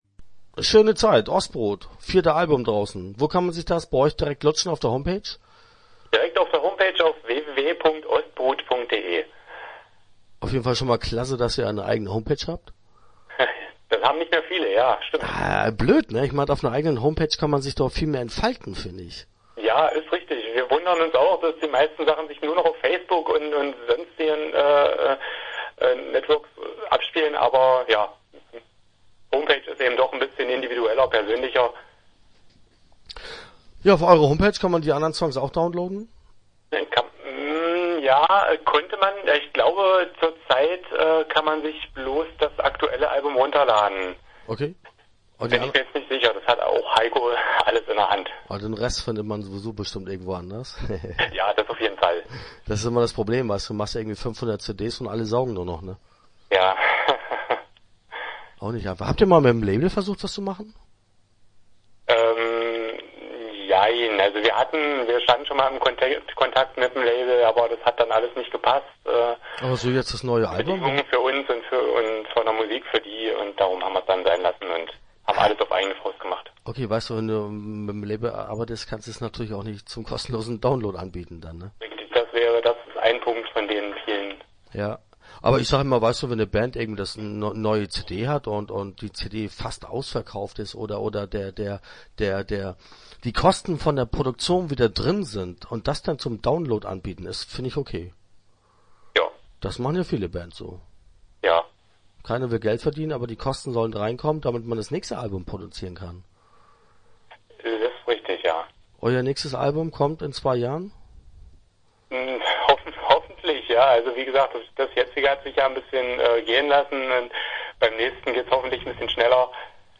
Start » Interviews » Ostbrut